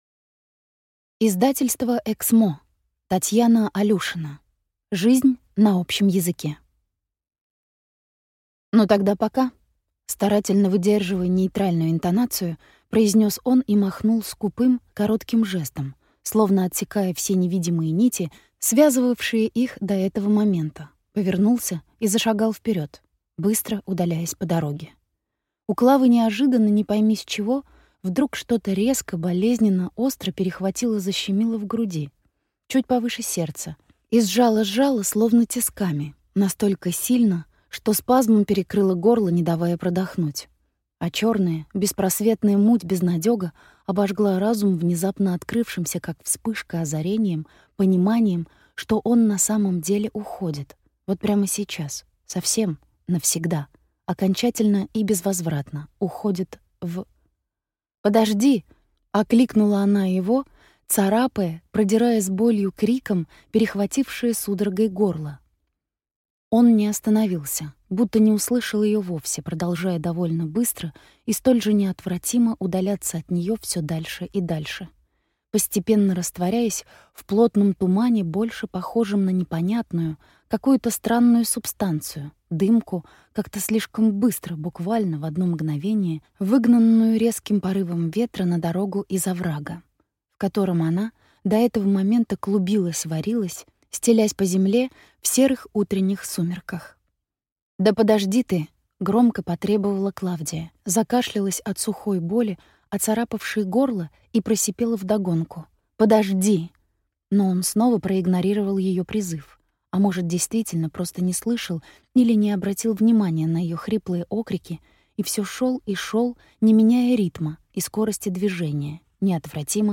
Аудиокнига Жизнь на общем языке | Библиотека аудиокниг